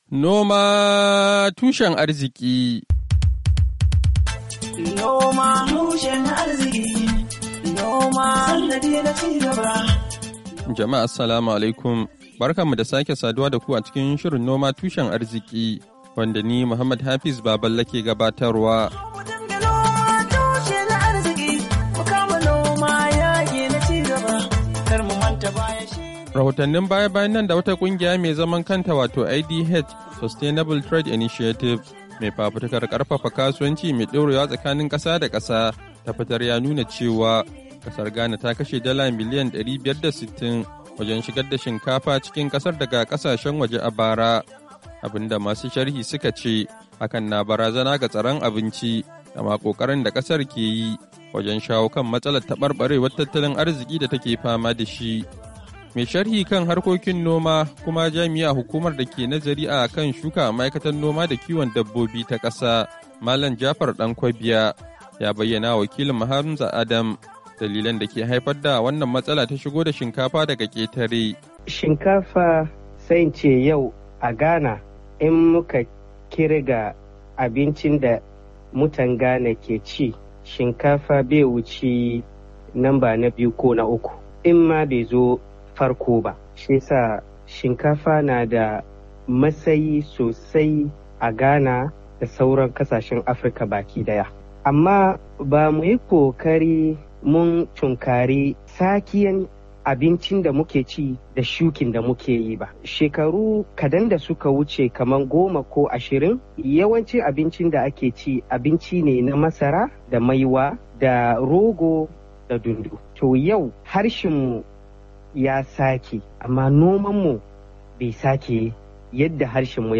Shirin noma tushen arziki na wannan makon ya tattauna da mai sharhi kan harkokin noma